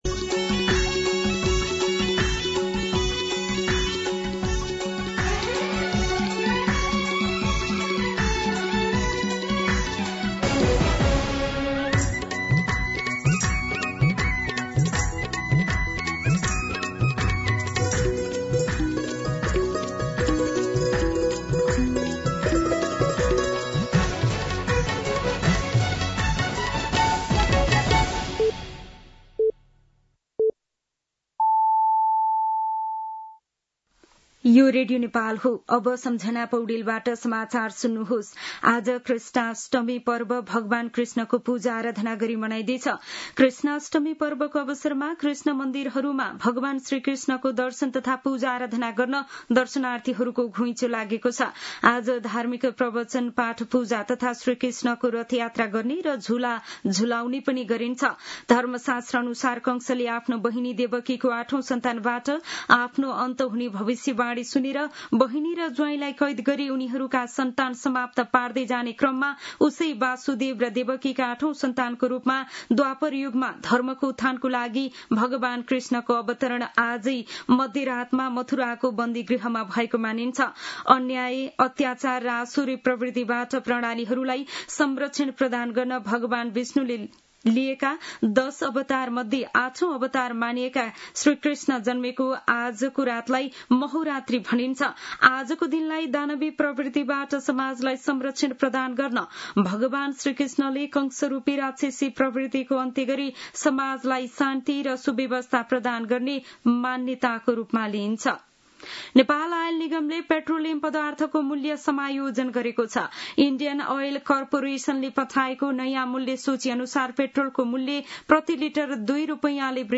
मध्यान्ह १२ बजेको नेपाली समाचार : ३१ साउन , २०८२